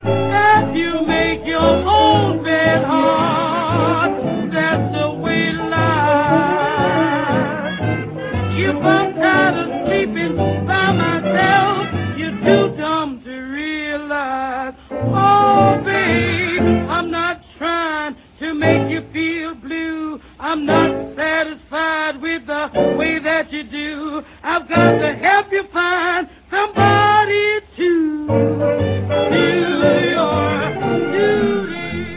и два мощных трагических блюза
Записи отличались высоким музыкальным и техническим уровнем.